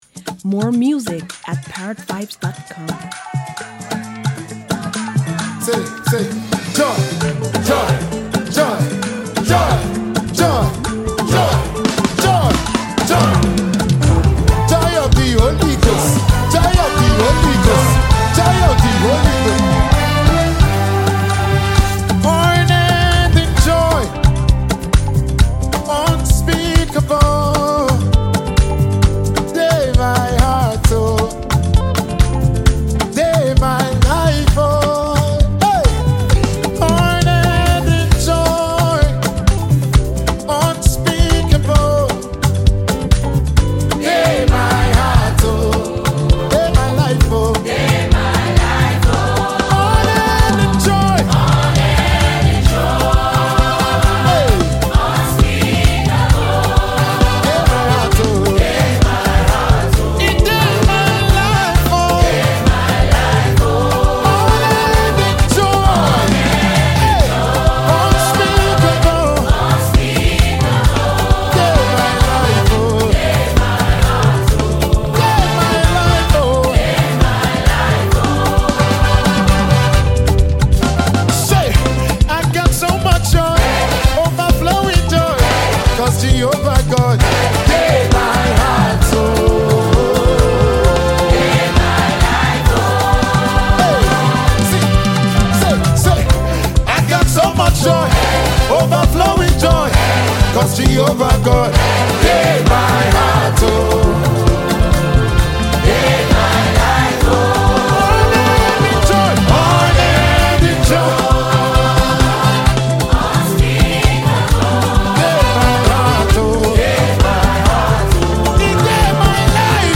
Impressively competent Nigerian gospel singer and songwriter
soul-stirring new song